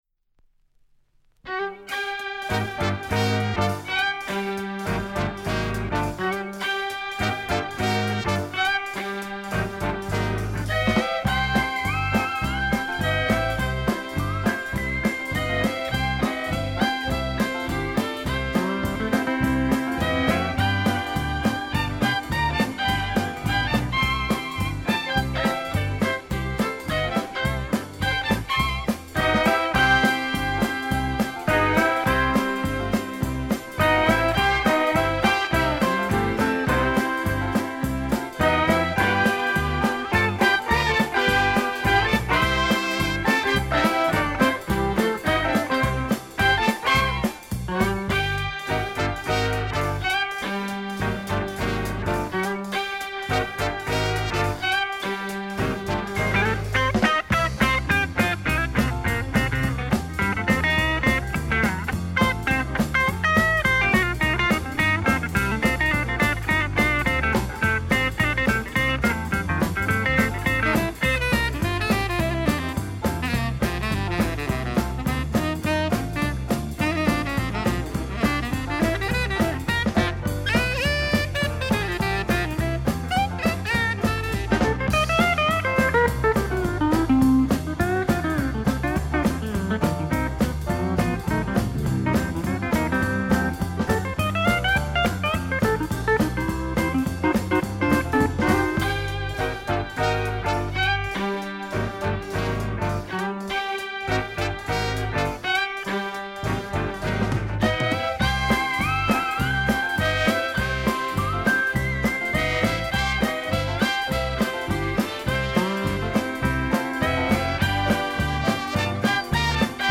guitar, banjo, mandolin
steel guitar
violin, piccolo
keyboards
trumpet, flugel
woodwinds
drums
vocals